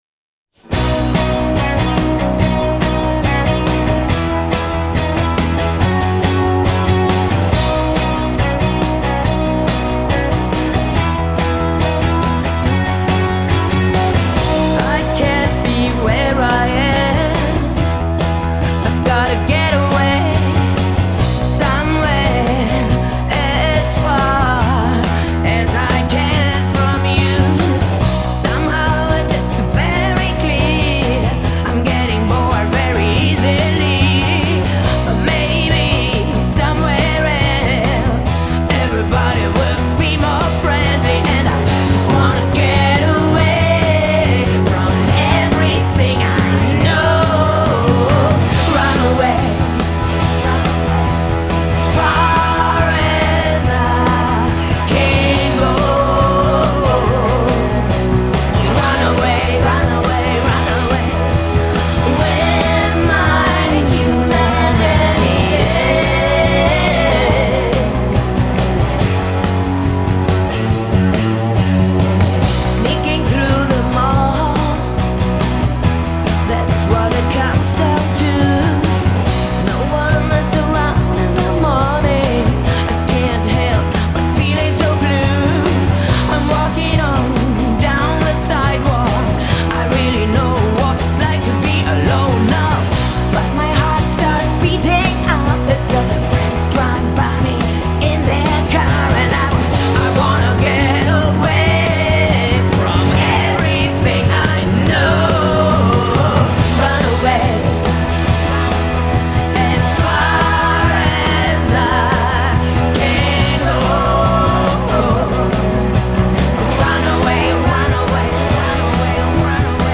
Панк Рок